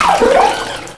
cries
-Replaced the Gen. 1 to 3 cries with BW2 rips.